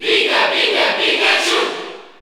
Category: Crowd cheers (SSBU) You cannot overwrite this file.
Pikachu_Cheer_French_PAL_SSBU.ogg